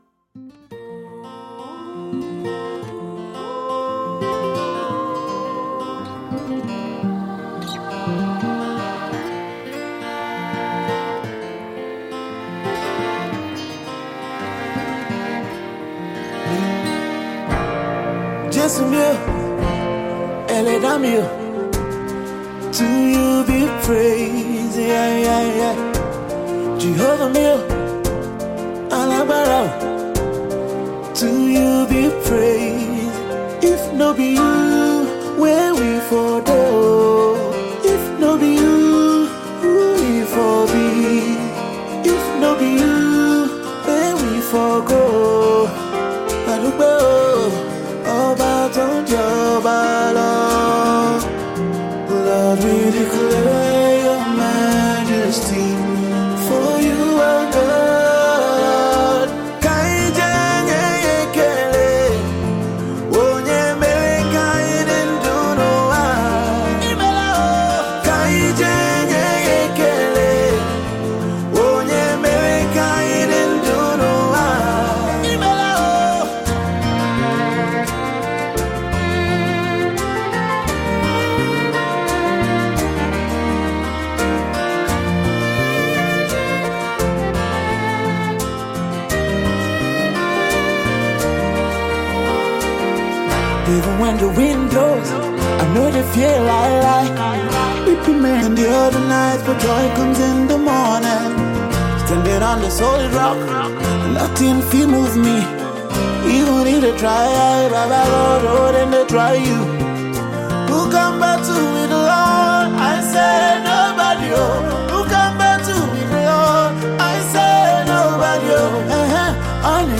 a Nigerian Afro Gospel Singer